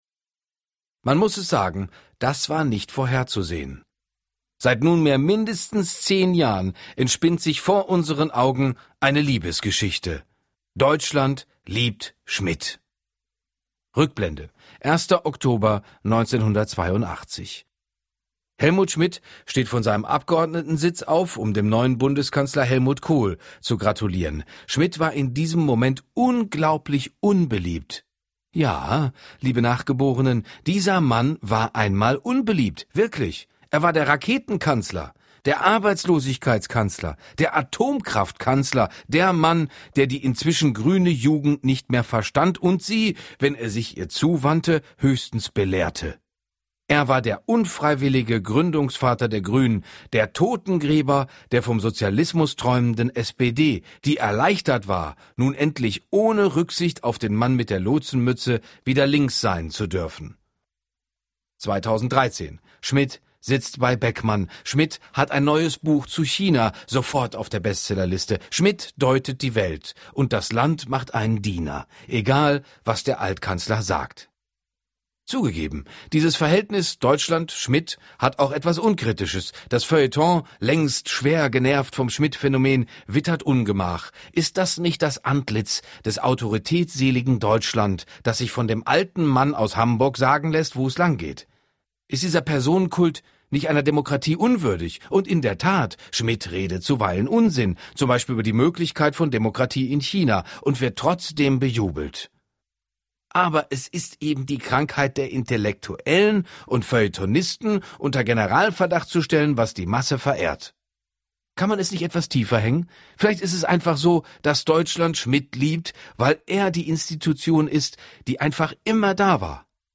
Jörg Thadeusz (Sprecher)